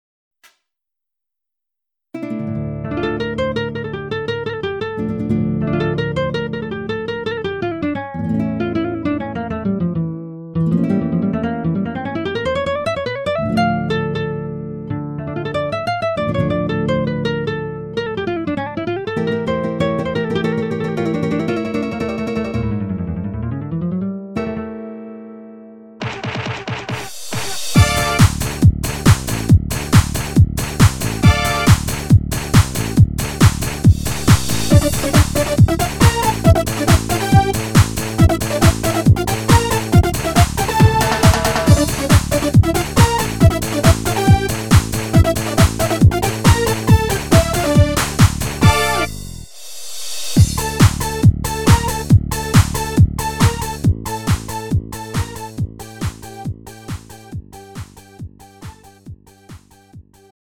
음정 원키
장르 가요 구분 Lite MR
Lite MR은 저렴한 가격에 간단한 연습이나 취미용으로 활용할 수 있는 가벼운 반주입니다.